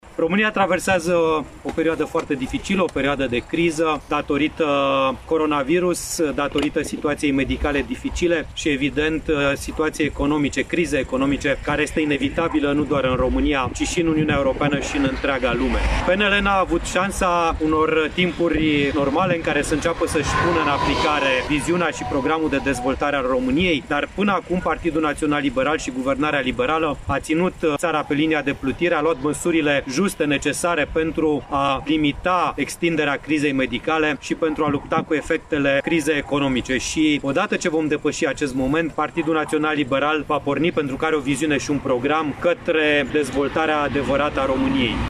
În faţa spitalului orăşenesc Bicaz, europarlamentarul a susţinut o conferinţă de presă, în care a prezentat principalele direcţii de acţiune ale PNL pentru perioada următoare.